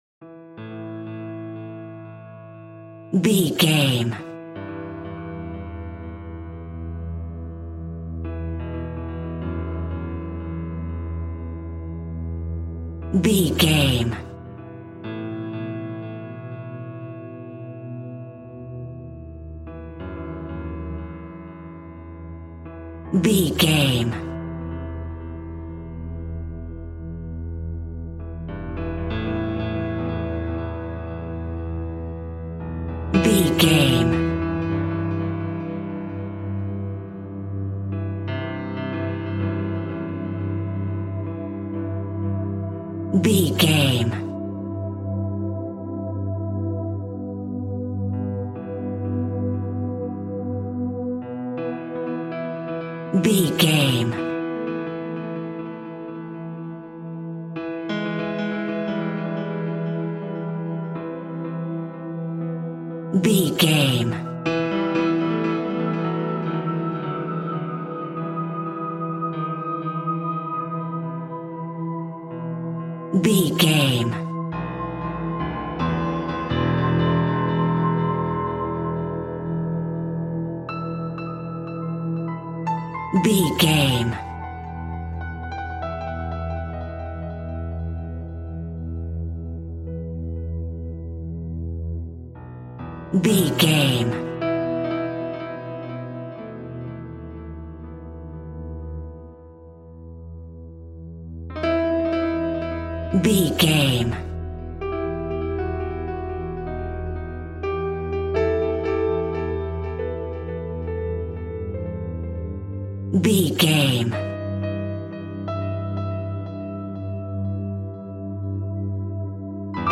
In-crescendo
Thriller
Aeolian/Minor
scary
tension
ominous
dark
haunting
eerie
strings
synth
ambience
pads